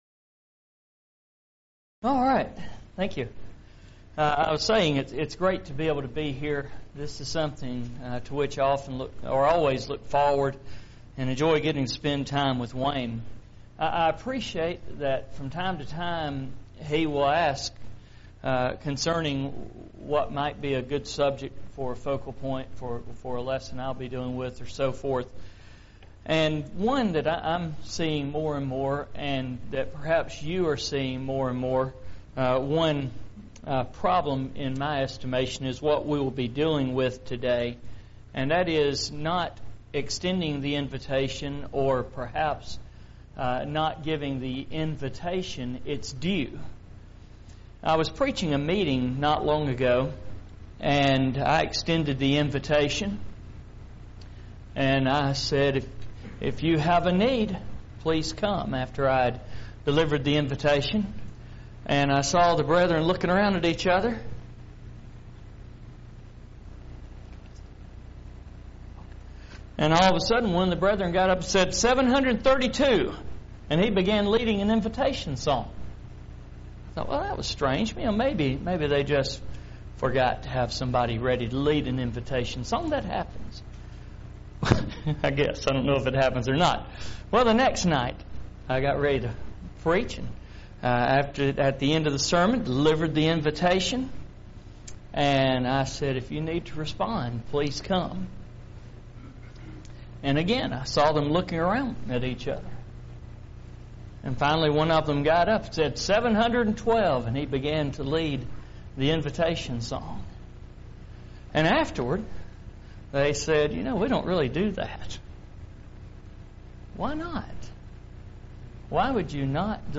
Preacher's Workshop
lecture